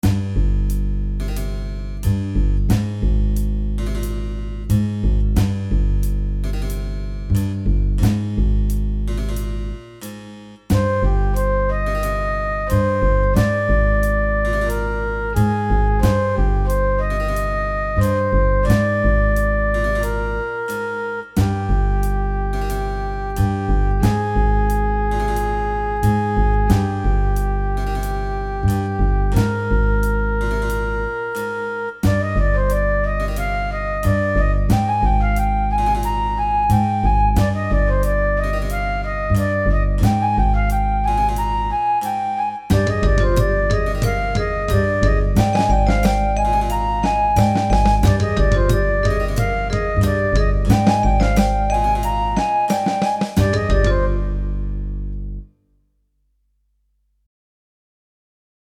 Pieza breve de jazz contemporaneo
contemporáneo
jazz